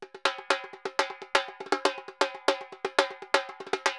Repique Salsa 120_2.wav